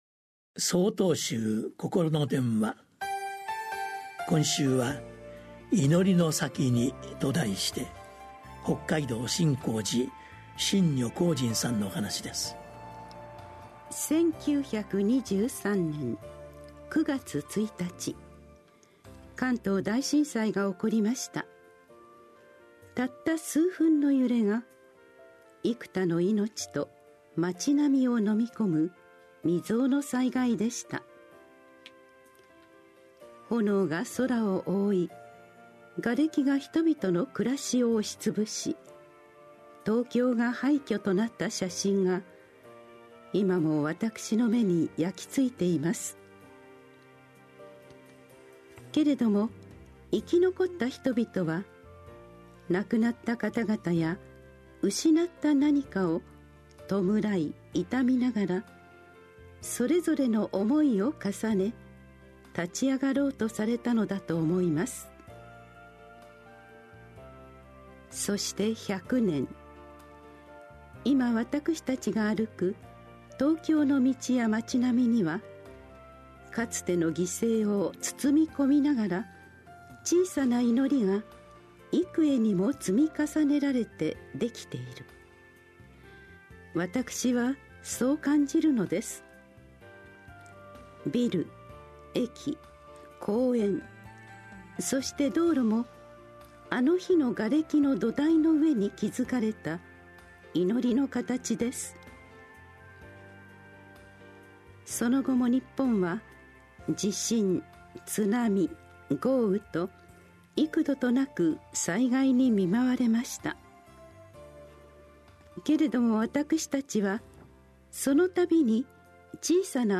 心の電話（テレホン法話）８/26公開『祈りのさきに』 | 曹洞宗 曹洞禅ネット SOTOZEN-NET 公式ページ